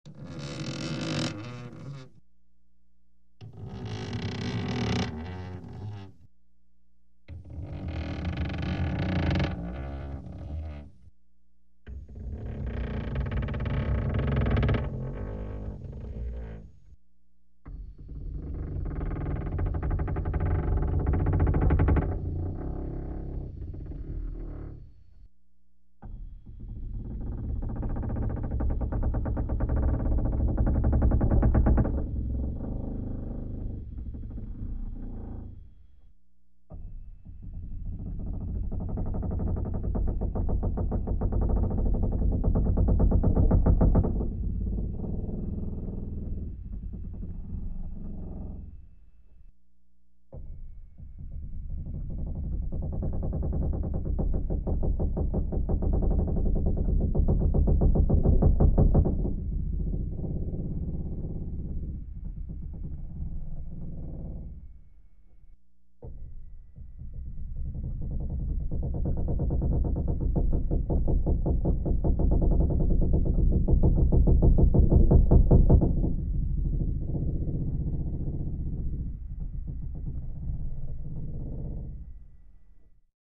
Скрип деревянной палубы судна